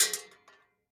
8abddf23c7 Divergent / mods / Bullet Shell Sounds / gamedata / sounds / bullet_shells / shotgun_metal_6.ogg 22 KiB (Stored with Git LFS) Raw History
shotgun_metal_6.ogg